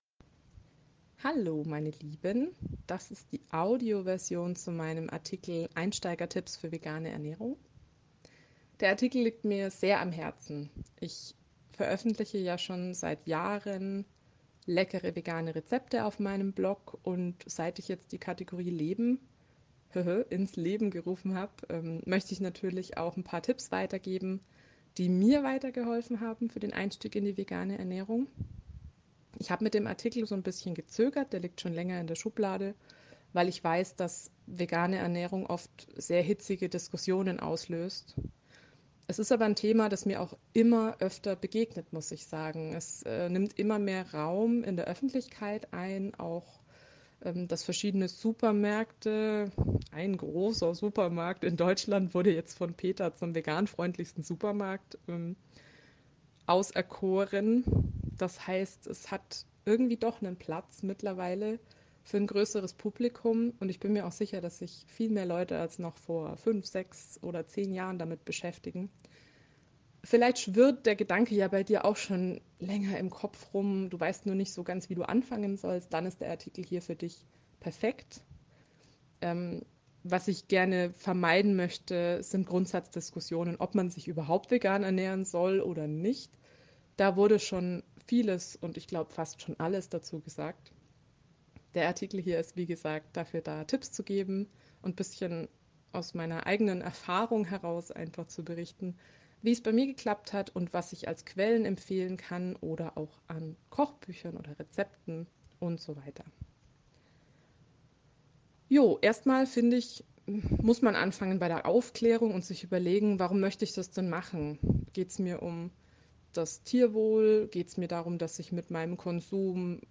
Dann höre Dir doch einfach diesen Artikel an – in der Audio-Version, mit zusätzlichen Anekdoten, Infos und Tipps.